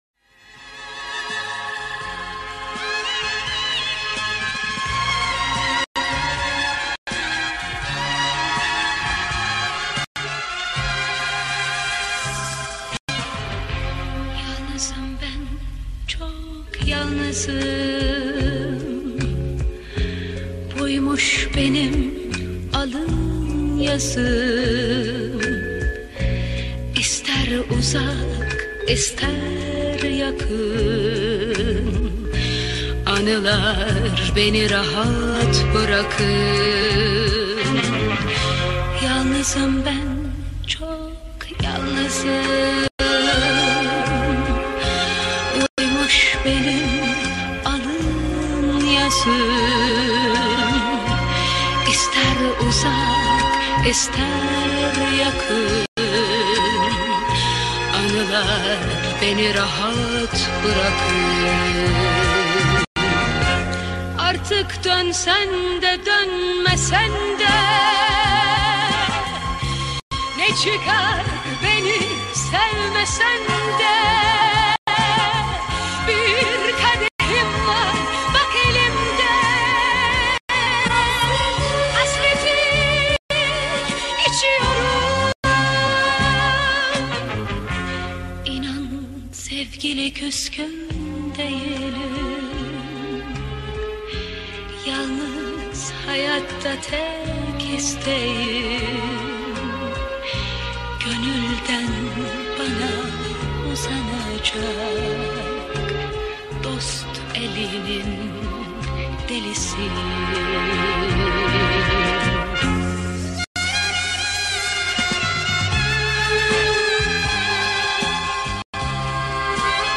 Turkish Pop, Pop